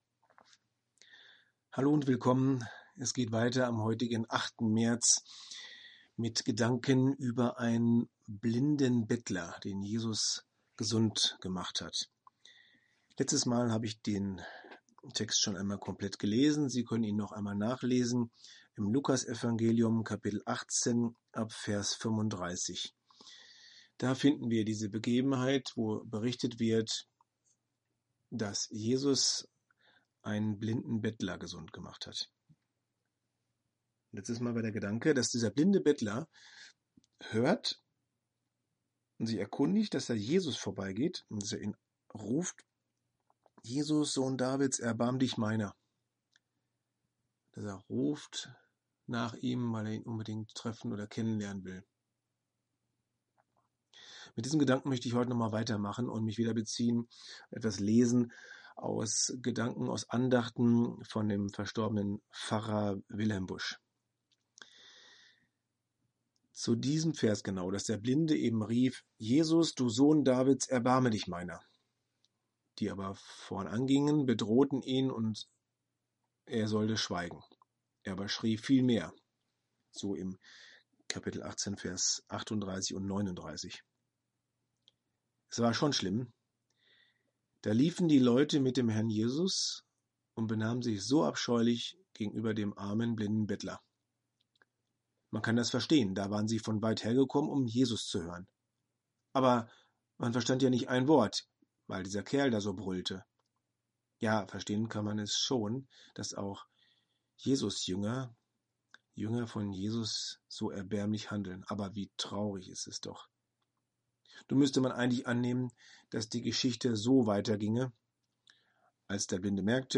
Audio-Andachten: Impulse, Denkanstöße über Gott und die Welt